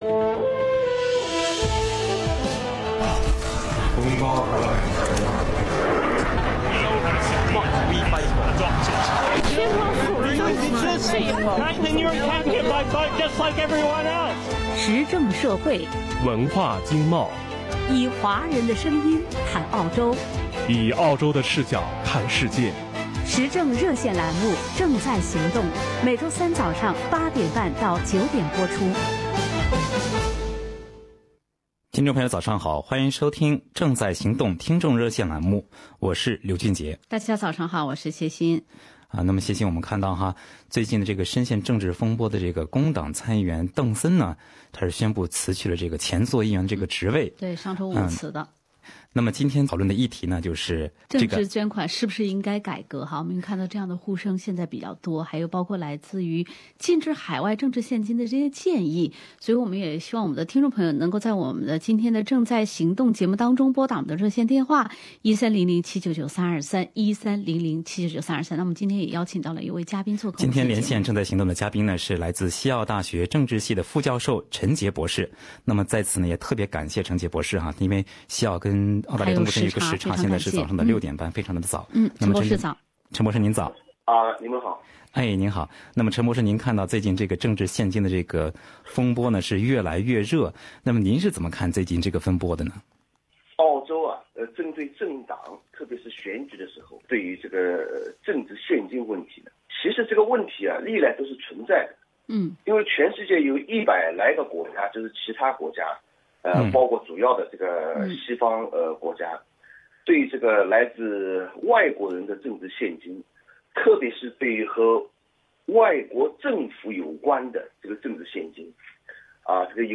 不少听众朋友也表达了自己的观点。